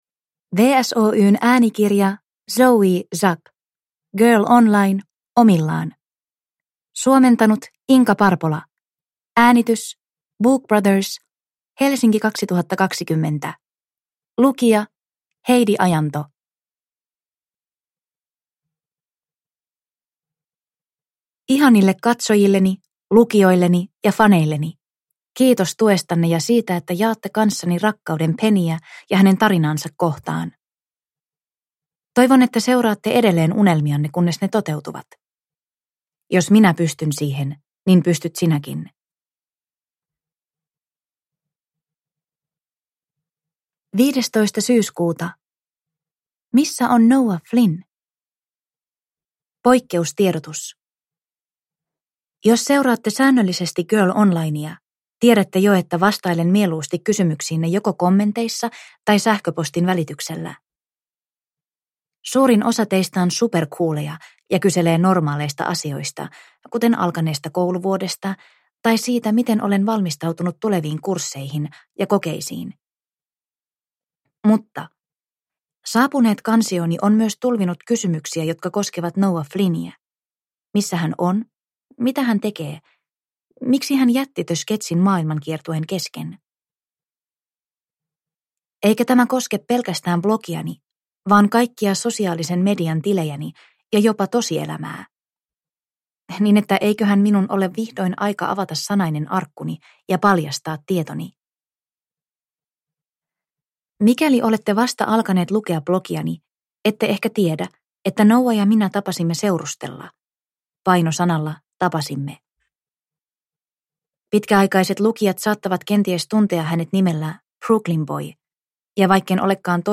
Girl Online omillaan – Ljudbok – Laddas ner